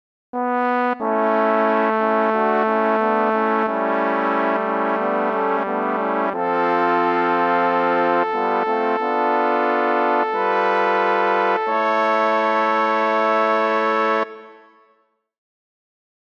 Key written in: A Major
How many parts: 4
Type: Barbershop
All Parts mix:
JK they're robo tracks